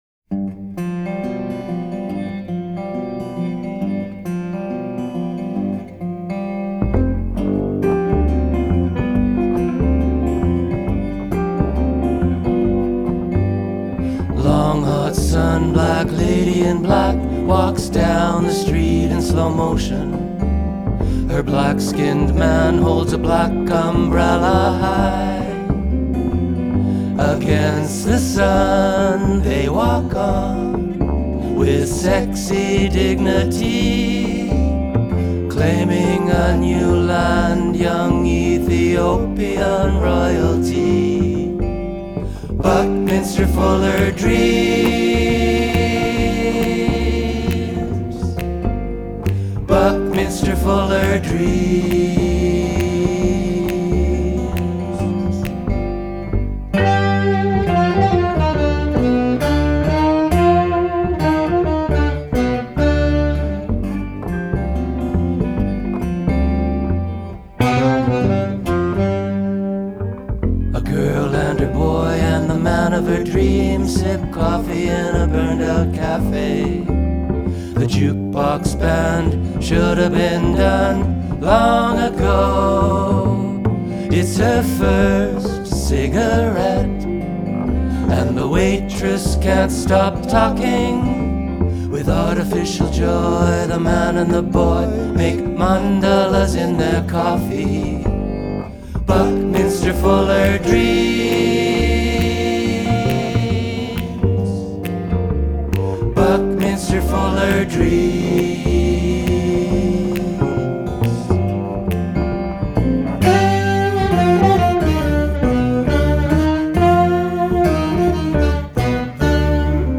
Recorded at the No Fun Club in Winnipeg in October 2025
acoustic guitar/vocals
sax/vocals
electric guitar/vocals
bass/vocals